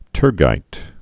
(tûrgīt, -jīt)